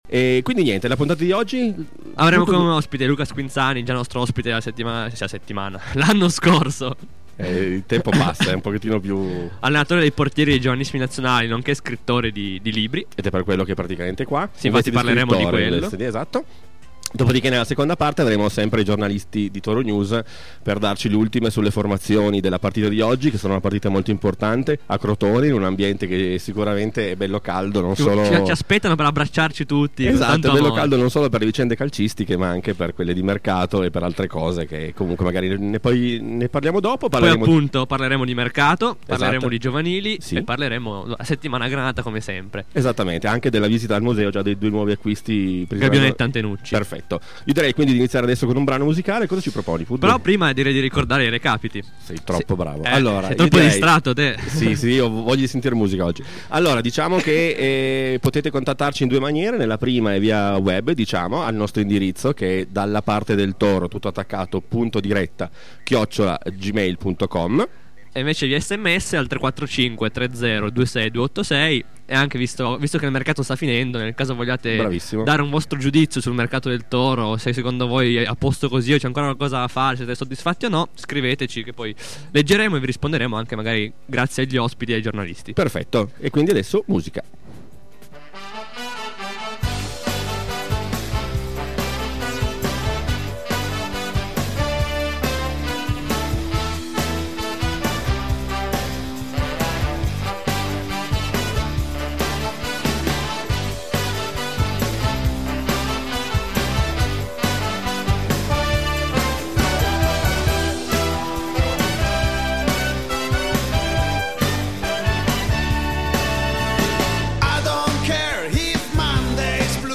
29-01-11 Ospite a Radio Flash “Dalla parte del Toro”.